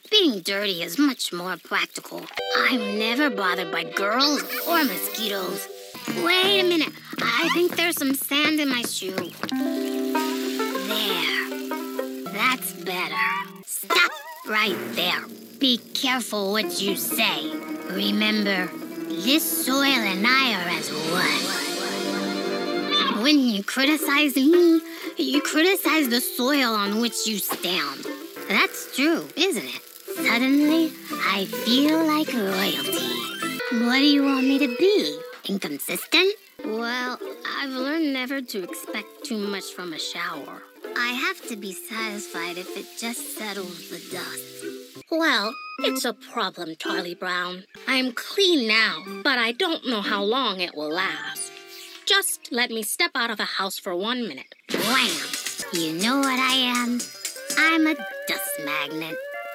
Animation
Voice Type:  Expressive, rich, conversational, with a touch of rasp.